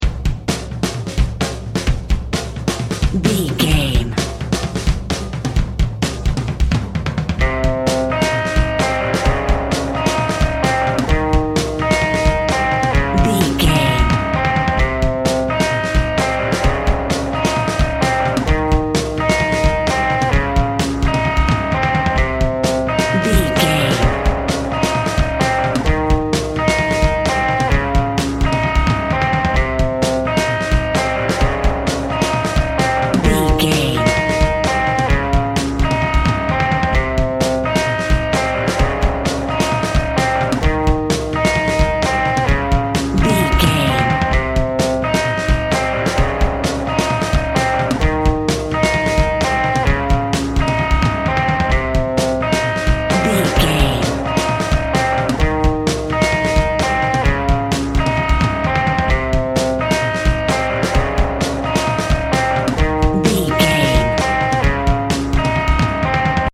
Rock Music with a Scary Twist.
Ionian/Major
Fast
energetic
driving
heavy
aggressive
electric guitar
bass guitar
drums
ominous
eerie
hammond organ
fender rhodes
percussion